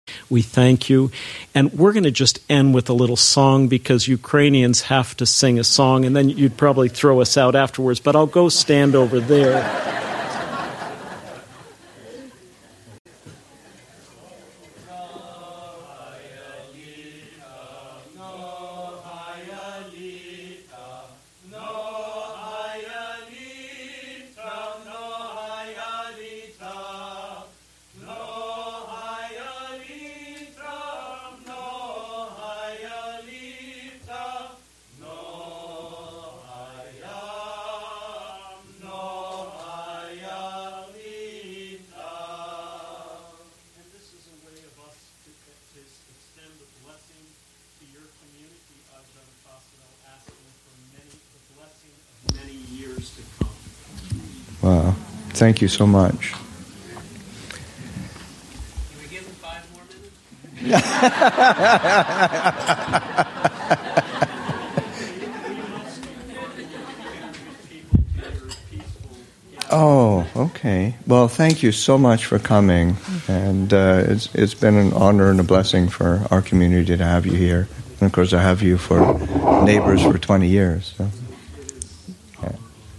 Chanting: The monks of Holy Transfiguration Monastery sing a blessing